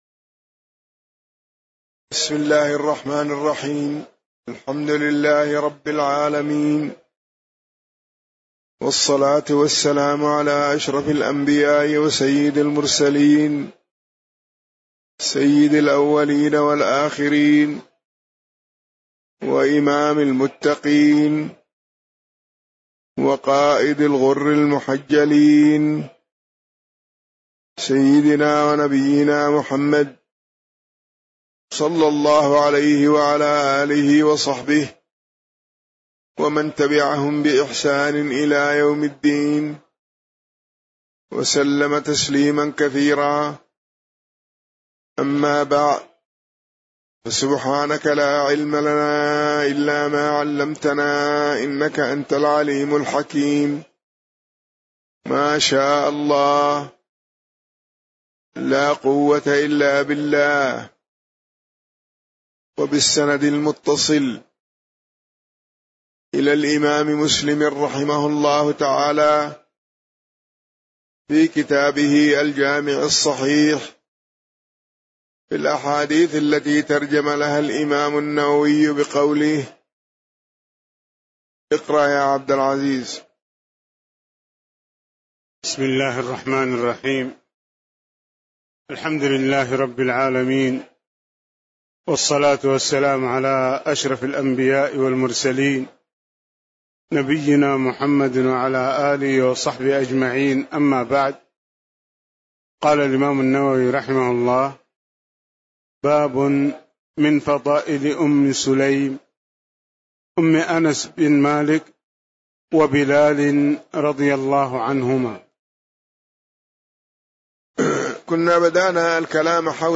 تاريخ النشر ١٤ رمضان ١٤٣٧ هـ المكان: المسجد النبوي الشيخ